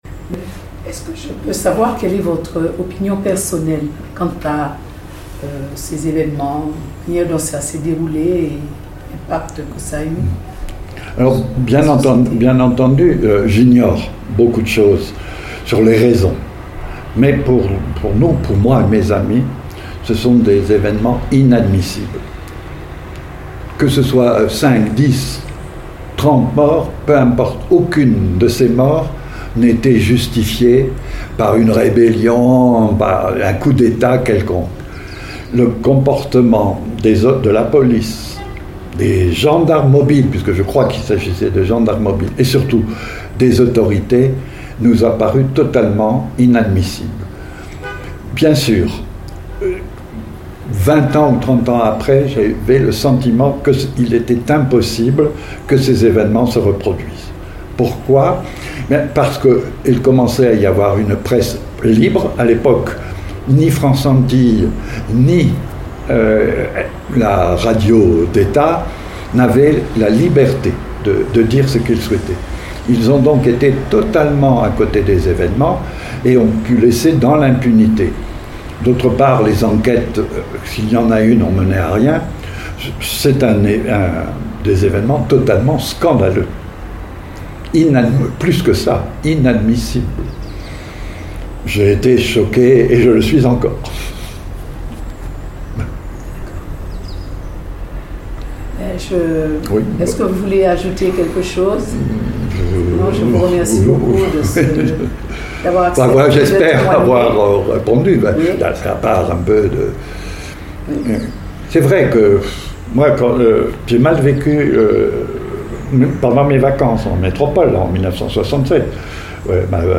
Intégralité de l'interview.